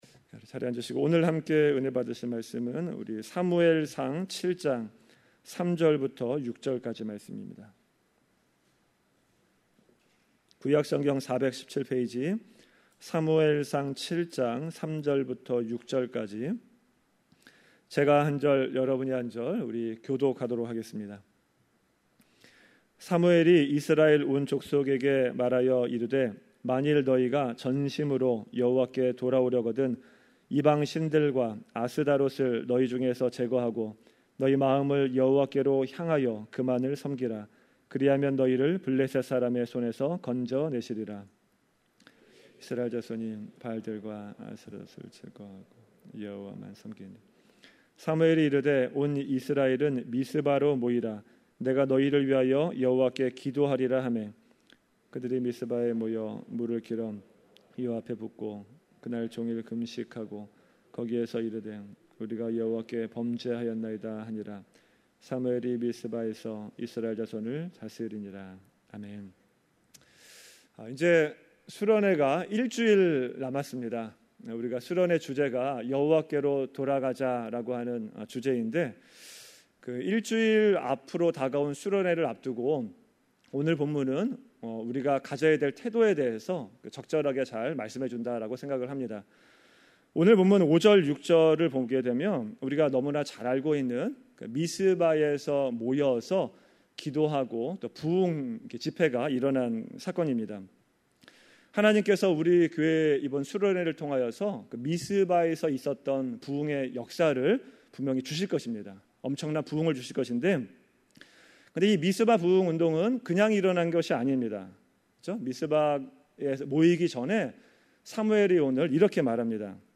Categories: 2019년 금요설교